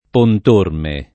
pont1rme] top. (Tosc.) — oggi sobborgo di Empoli, sul torrente Orme — da una variante ant. del top., il soprann. il Pontormo [il pont1rmo] del pittore I. Carrucci o Carucci (1494-1556): tra i suoi contemporanei, talvolta, il Puntormo [il punt1rmo]